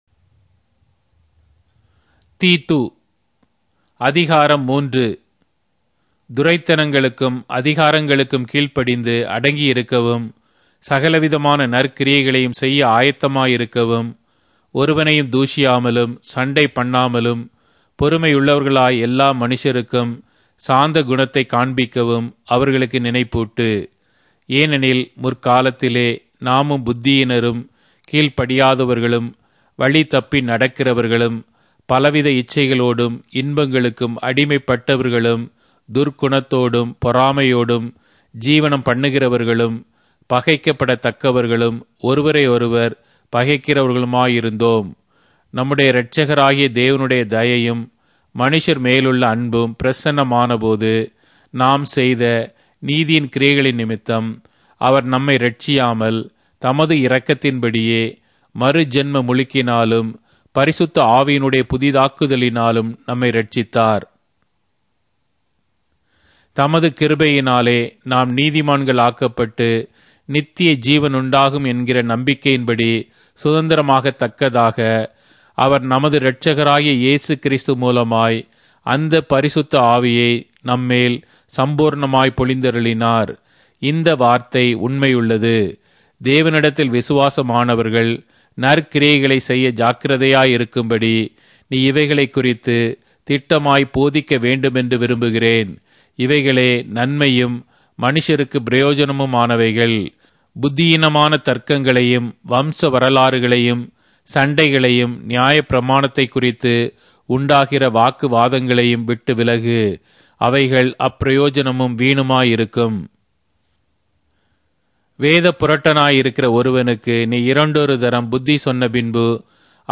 Tamil Audio Bible - Titus All in Irvor bible version